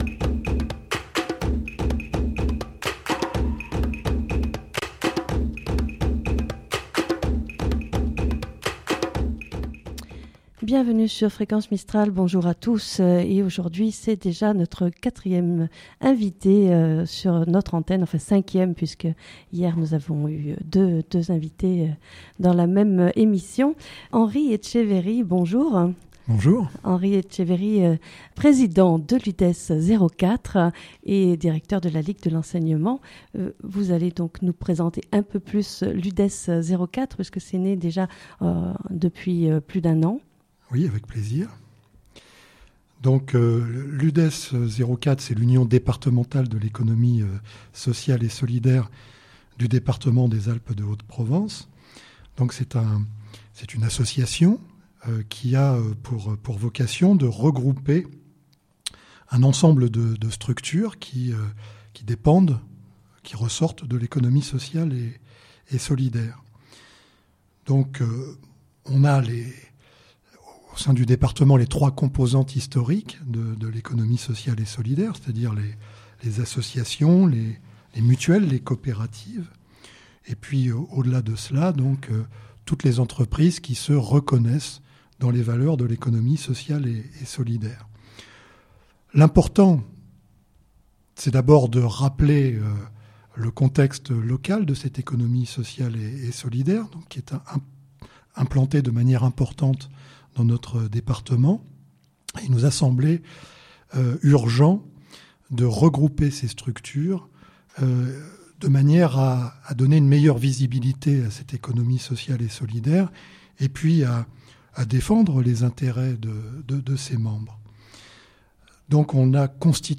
Novembre, c'est le Mois de l'Économie Sociale et Solidaire ! Fréquence Mistral reçoit chaque jour en direct du studio de Digne, des acteurs de l'Economie sociale et solidaire.